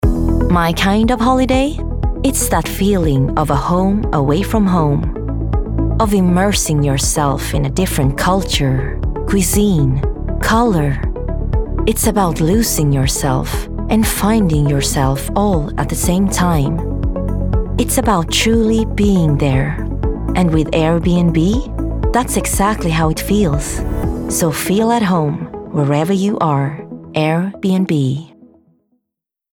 Airbnb - Positive, Friendly, Calm